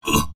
文件 文件历史 文件用途 全域文件用途 Enjo_dmg_02_1.ogg （Ogg Vorbis声音文件，长度0.4秒，187 kbps，文件大小：8 KB） 源地址:地下城与勇士游戏语音 文件历史 点击某个日期/时间查看对应时刻的文件。